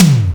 TomLow.wav